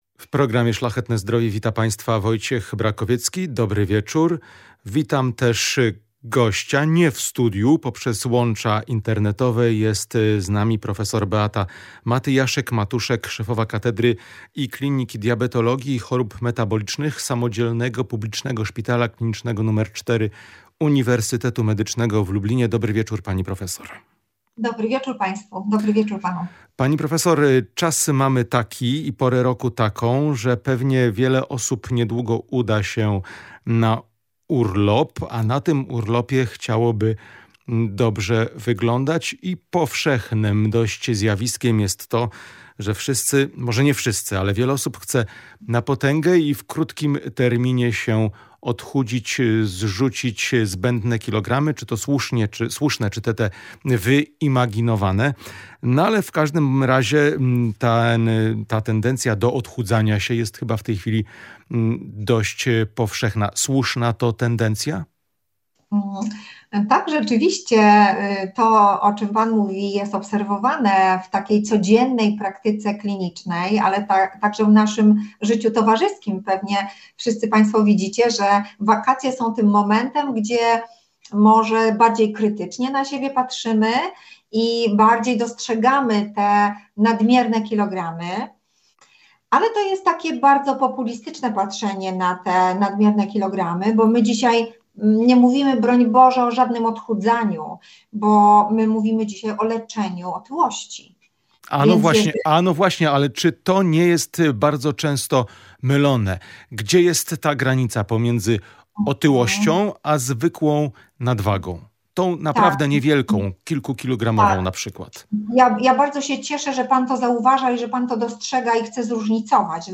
O tym wszystkim w rozmowie z gościem programu